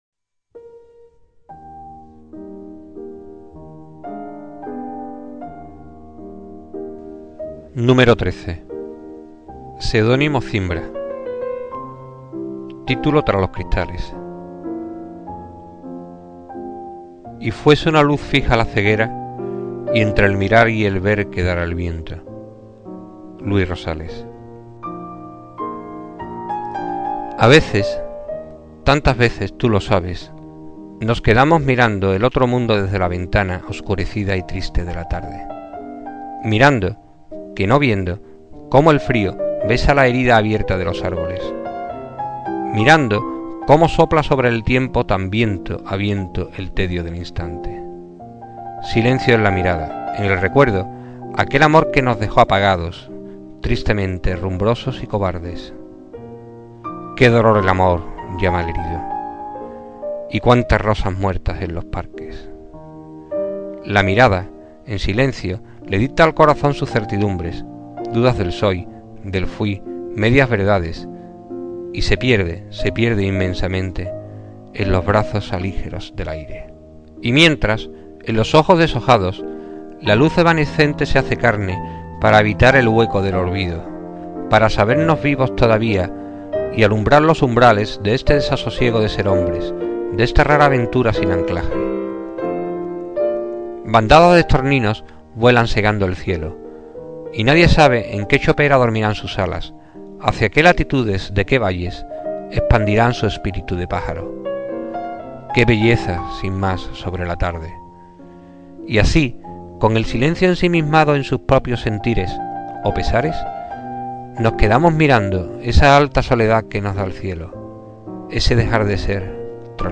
Inicio Multimedia Audiopoemas Tras los cristales.